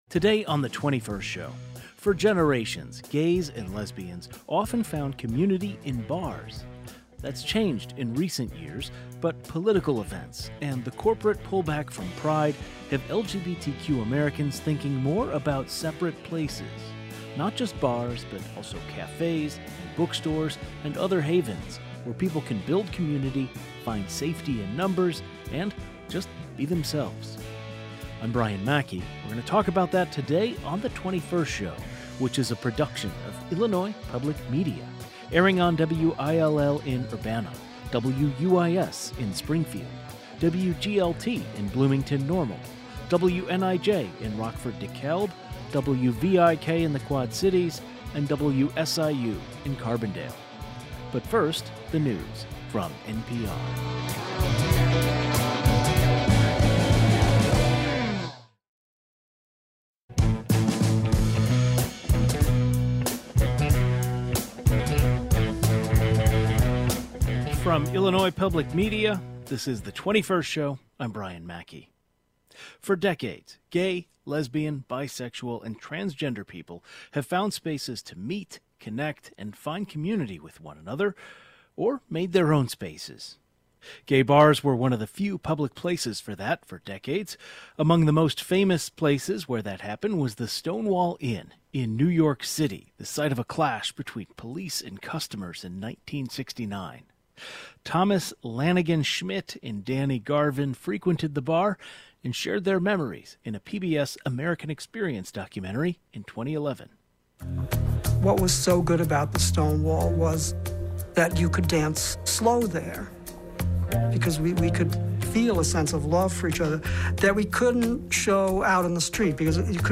Business owners who offer safe spaces to their local LGBTQ communities in downstate Illinois discuss what these spaces mean to people in the current political environment.